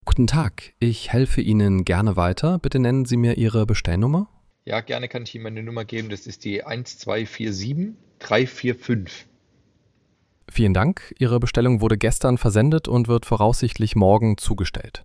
Exzellenter Kundenservice ist unsere Leidenschaft mit den natürlich menschlichen Stimmen unserer Sprachassistenten.
Sprachbeispiel: